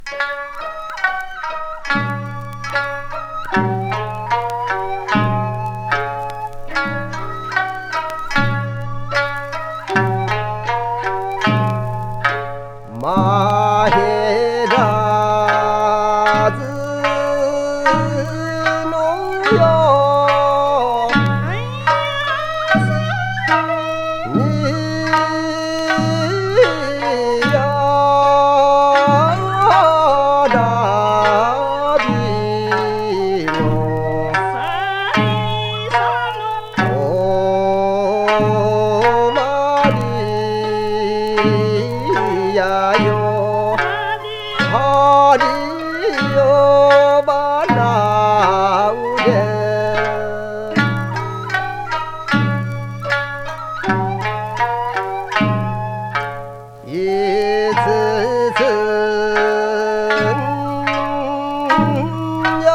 やさしい歌声に癒される
Japanese 民謡